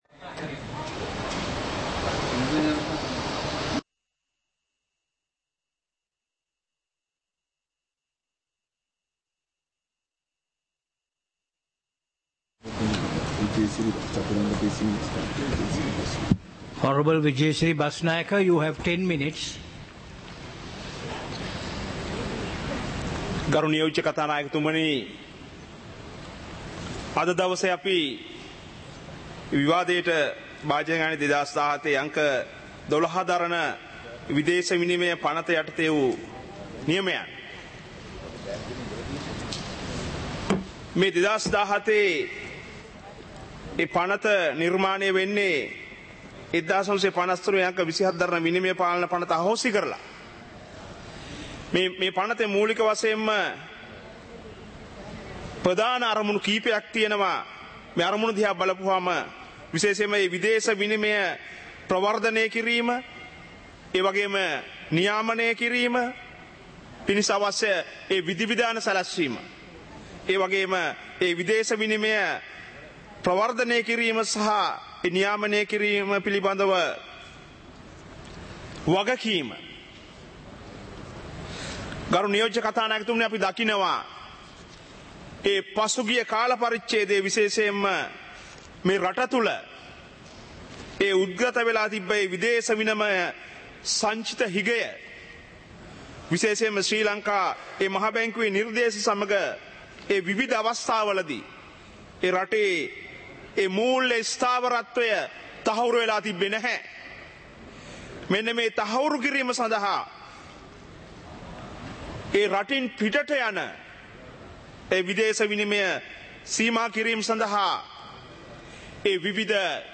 சபை நடவடிக்கைமுறை (2026-03-03)